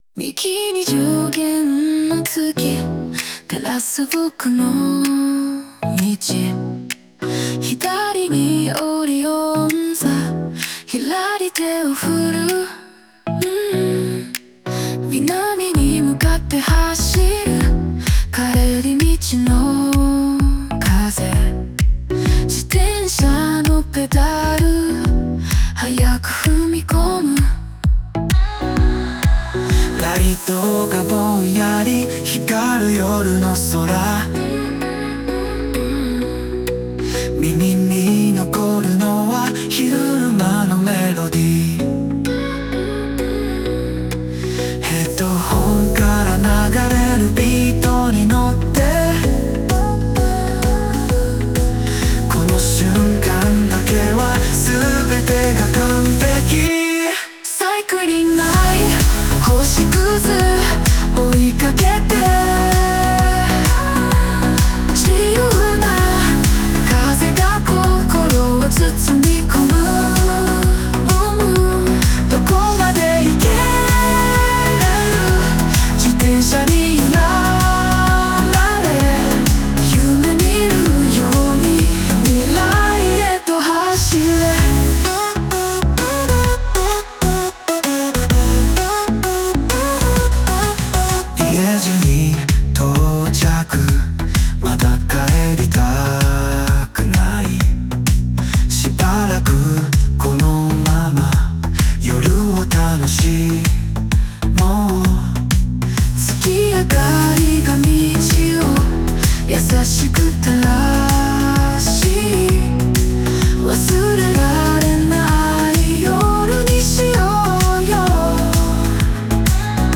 男女デュオ
ジャンル: ポップ、オルタナティブ 活動開始